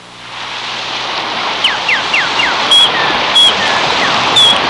Beach Intro Sound Effect
beach-intro.mp3